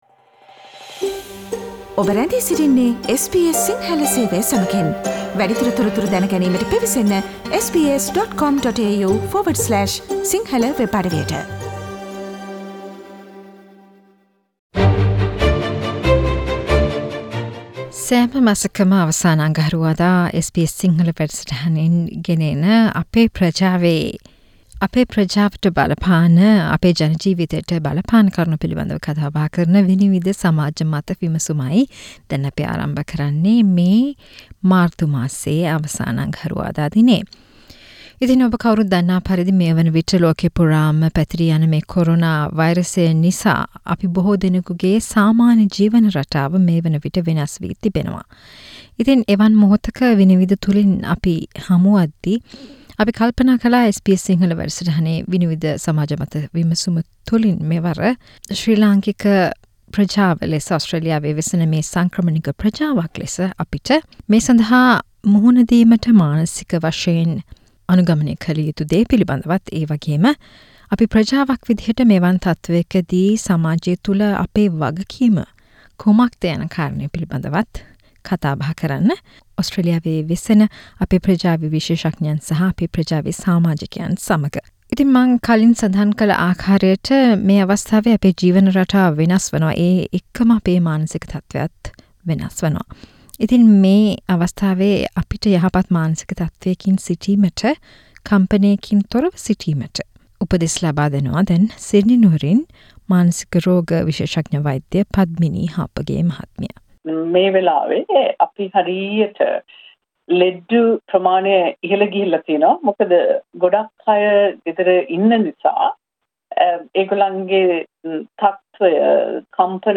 Vinivida monthly discussion on our responsibility at this Covid-19 pandemic